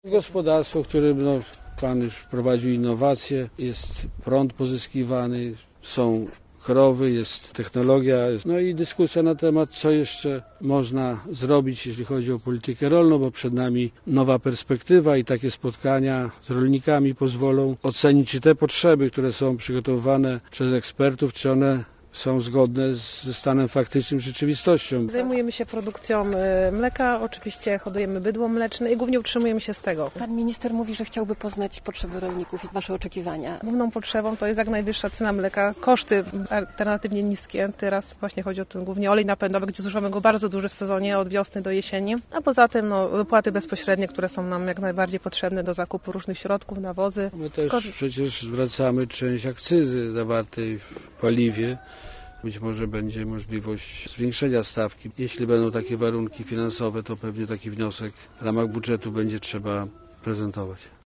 Nazwa Plik Autor Minister Krzysztof Jurgiel z wizytą w gospodarstwie rolnym audio (m4a) audio (oga) Minister podkreślał, że wiele spraw w ostatnich latach udało się załatwić, na przykład usprawnienie wypłat dopłat bezpośrednich.